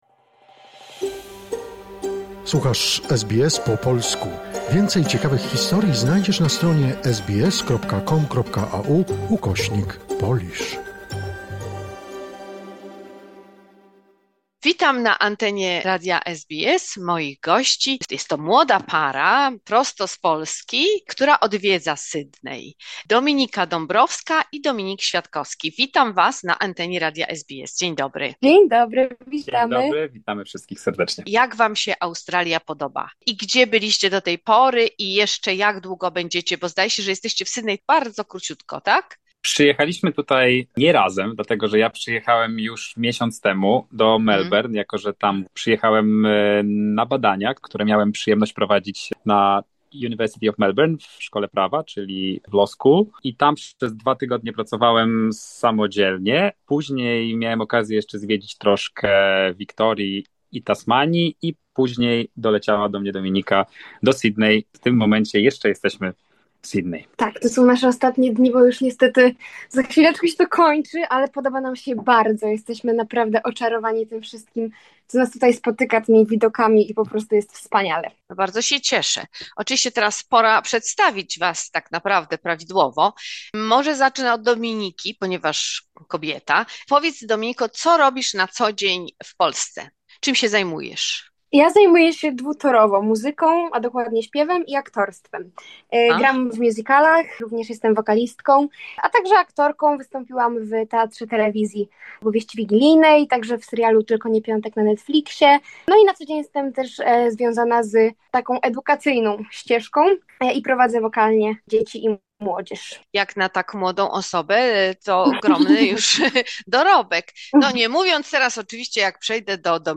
Pierwsza część rozmowy